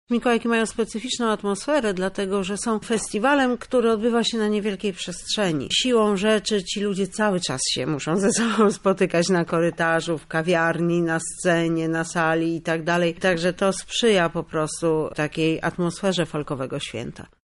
Dlaczego warto przyjść na mikołajki Folkowe mówi